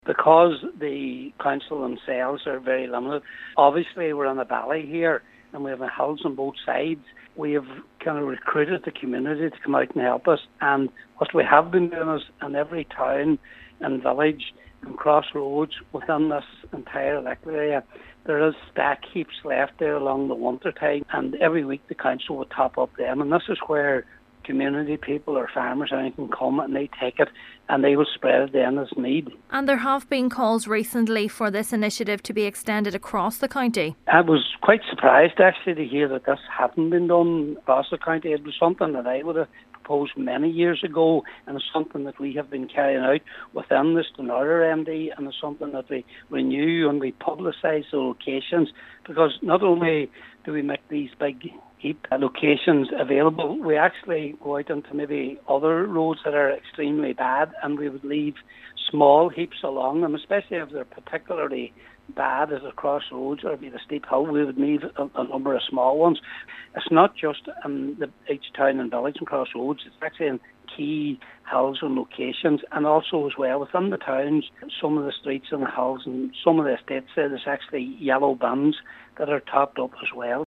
Councillor Patrick McGowan has welcomed the update and renewal of the initiative but says he was surprised to learn that it has not been rolled out countywide: